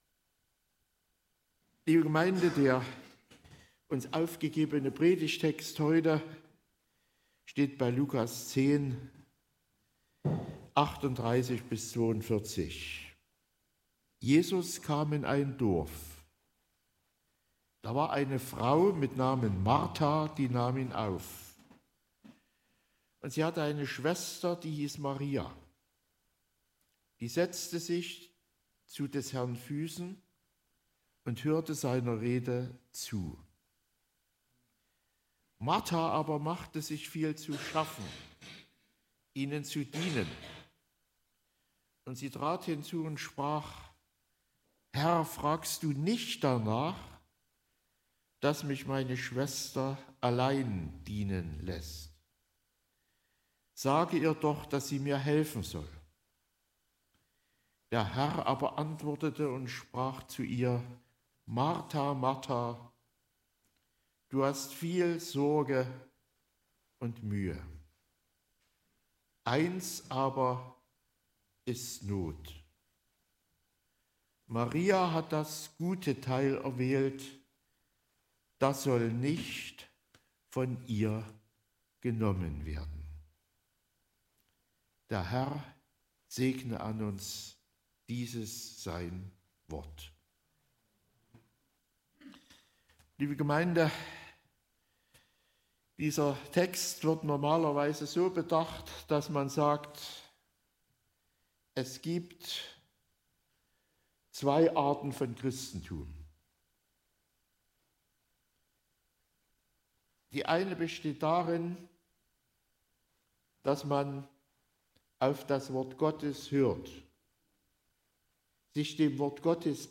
38-42 Gottesdienstart: Predigtgottesdienst Obercrinitz Zum Glauben gibt es zwei Meinungen